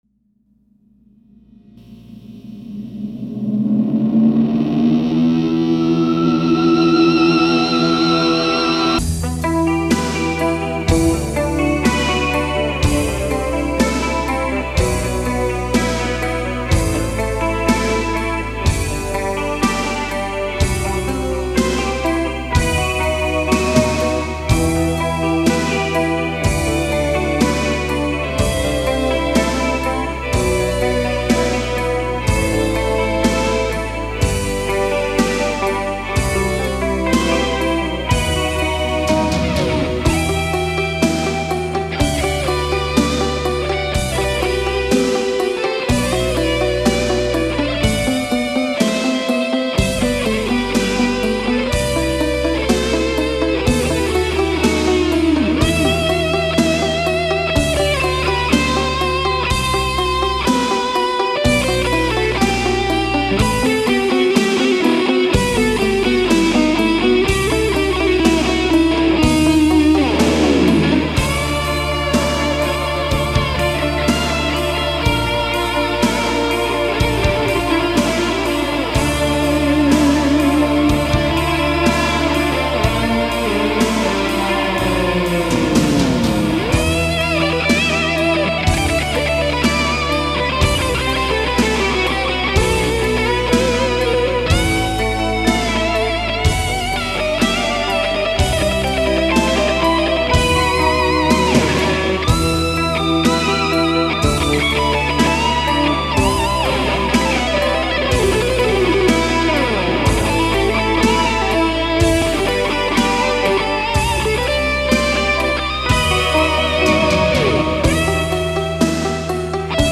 1990 – 4-Track DEMO „Never Ending“